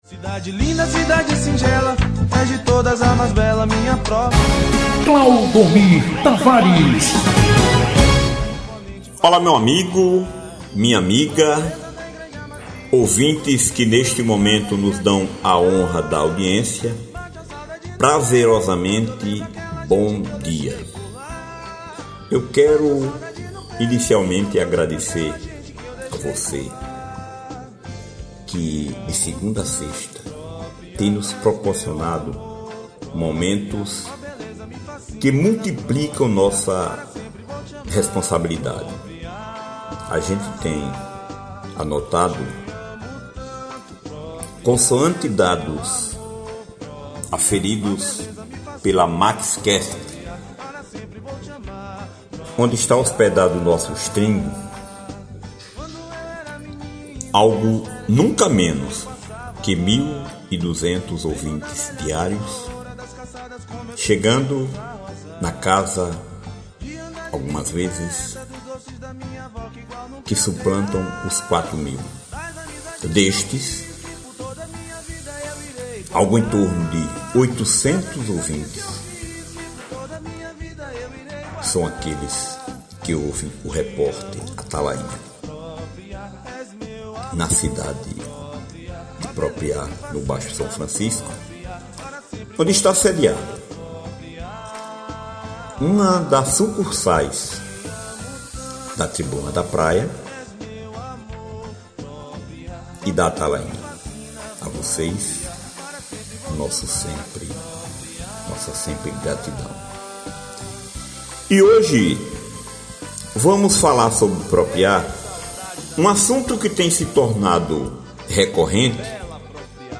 Editorial do REPÓRTER ATALAINHA desta quarta-feira (07) apreswntado